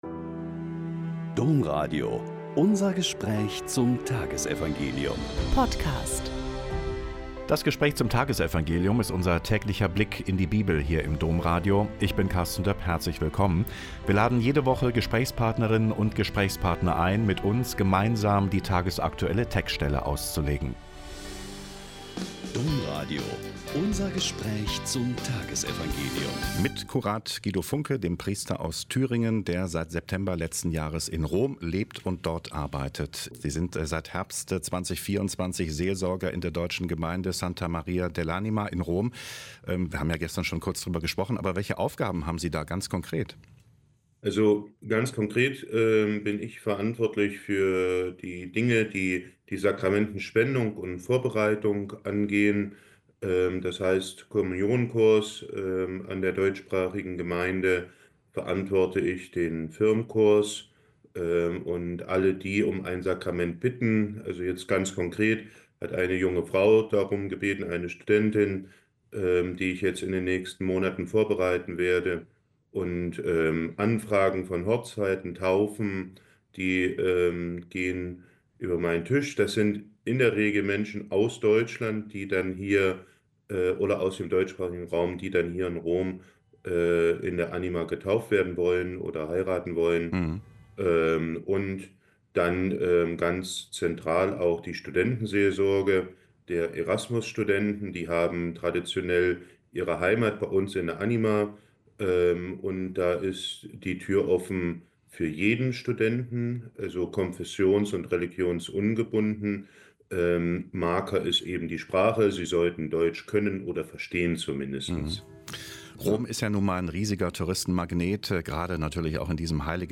Mk 5,21-43 - Gespräch